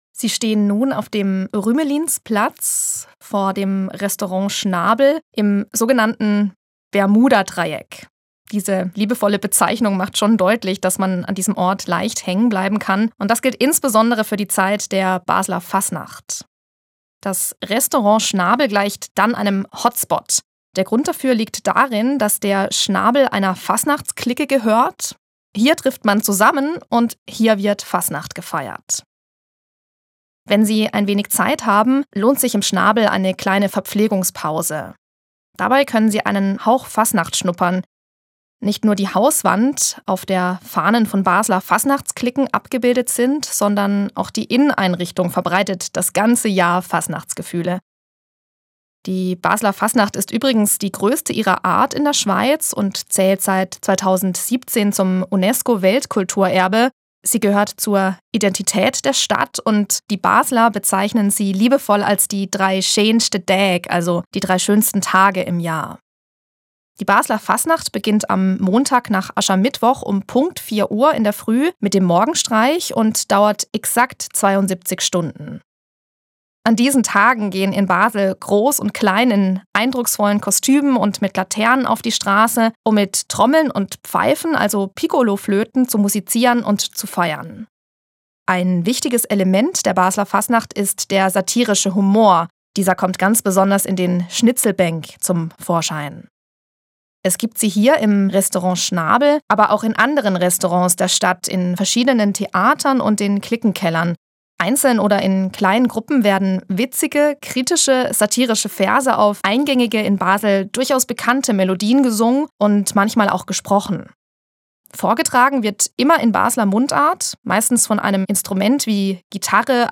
ORT: Rümelinsplatz, 4051 Basel
QUELLEN der Musikbeispiele: